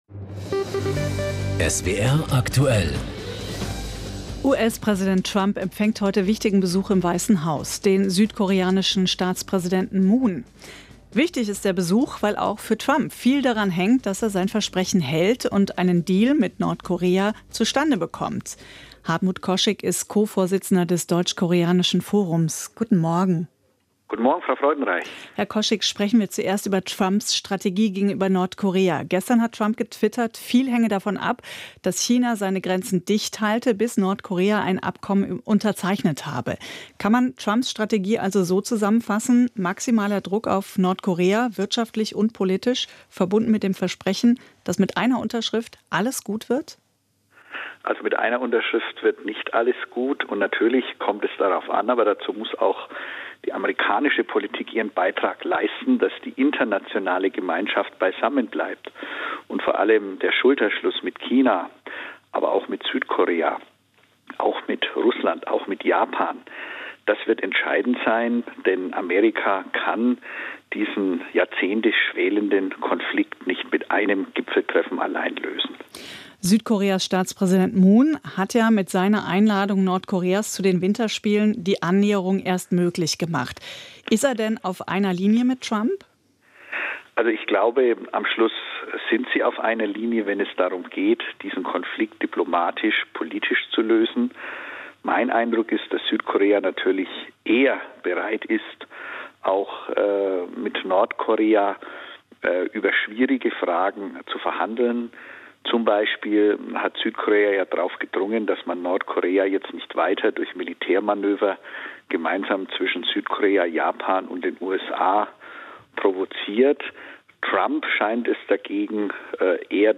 Südkoreas Präsident Moon Jae-in in Washington / Koschyk im Interview mit SWR
Der Radiosender „SWR Aktuell“ befragte hierzu den Ko-Vorsitzenden des Deutsch-Koreanischen Forums und Korea-Experten, Hartmut Koschyk.
Das Interview mit Hartmut Koschyk als mp3 Datei zum Nachhören finden Sie hier.